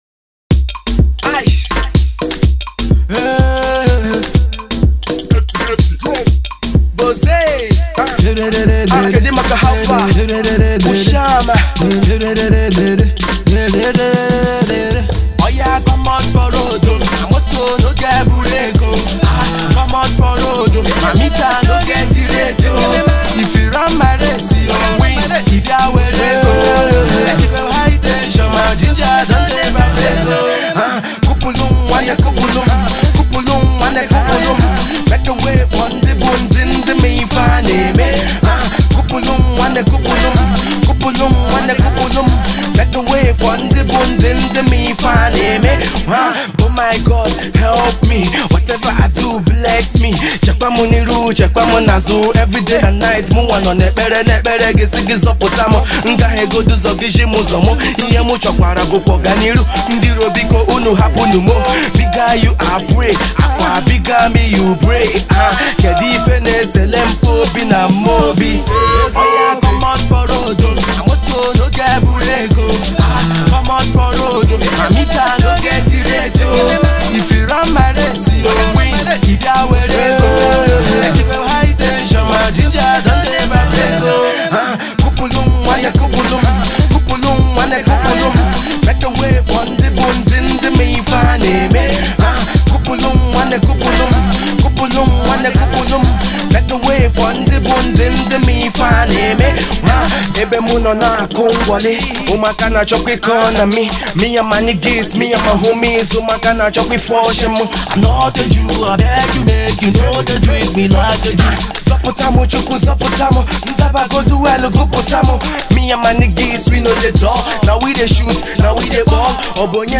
a pop single